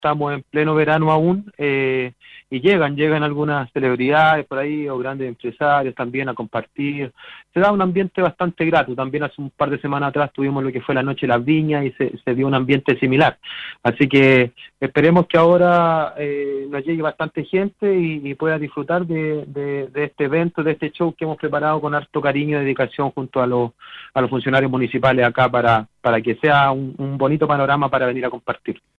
Lo anterior, fue detallado por el alcalde Patricio Rivera (IND) en conversación con el programa "Sala de Prensa" de VLN Radio (105.7 fm).